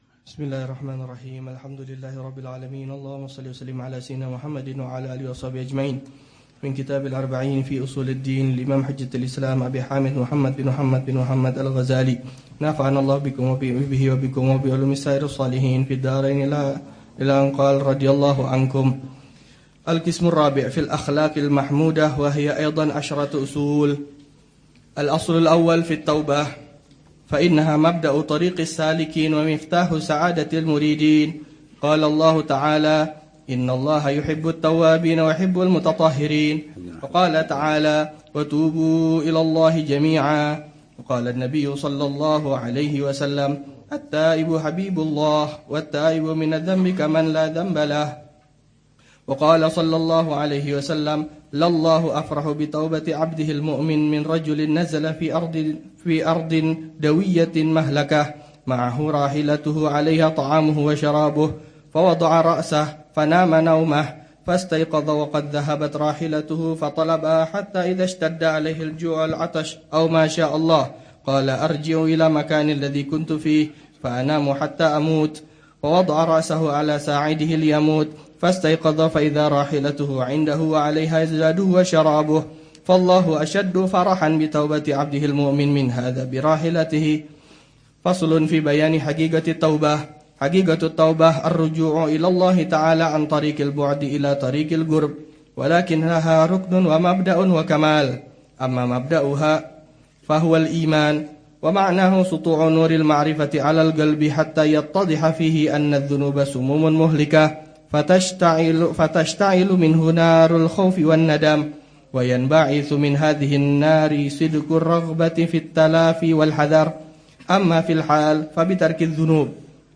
الدرس الثاني والثلاثون